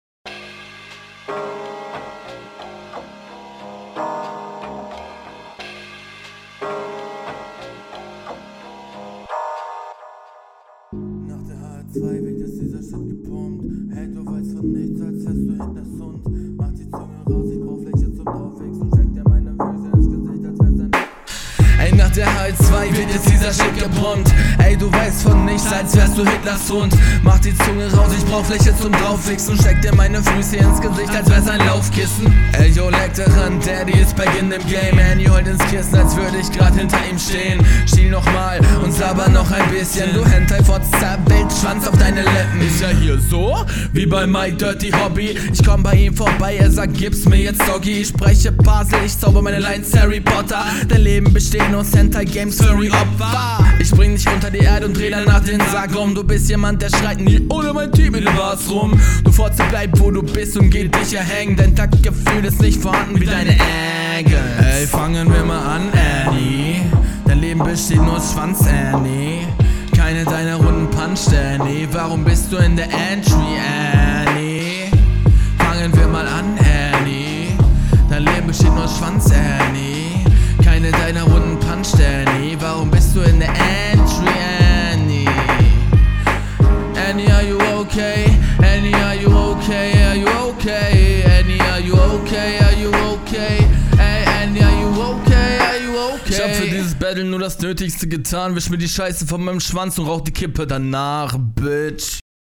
Da sind wir wieder bei Boom Bap.